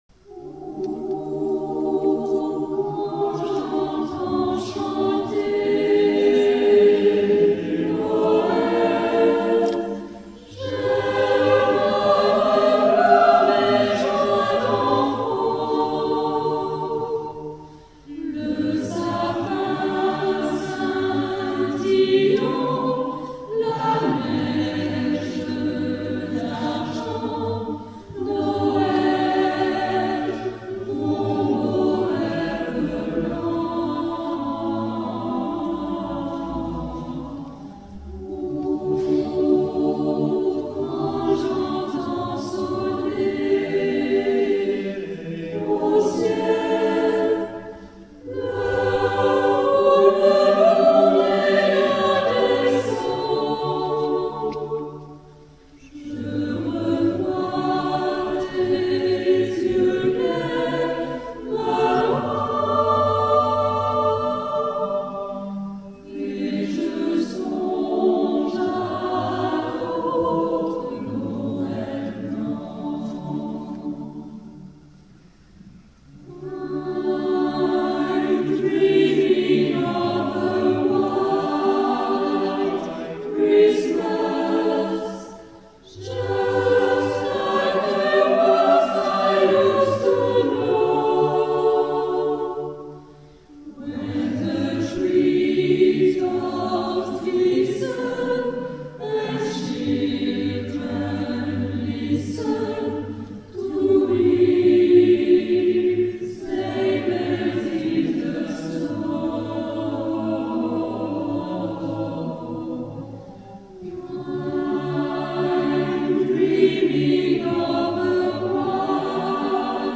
Concert Noël 2003 - Eglise de Sautron
Chant de Noël